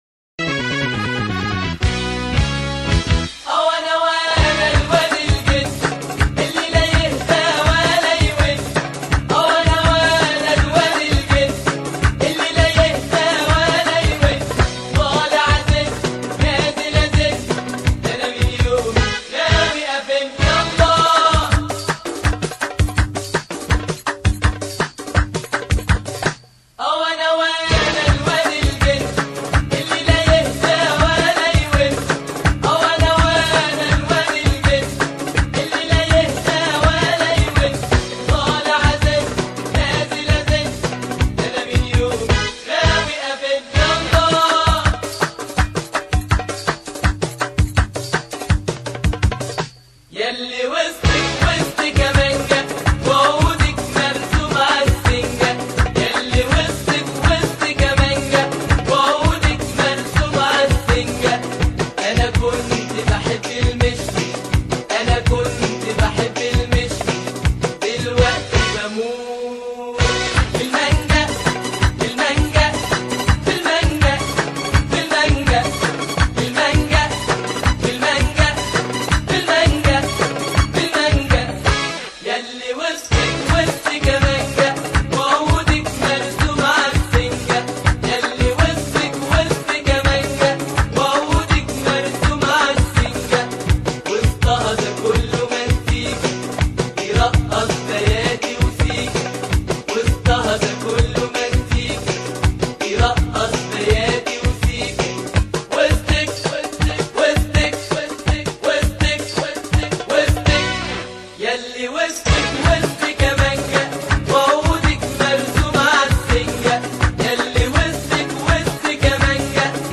غناء المجموعه
فلكلور شعبيي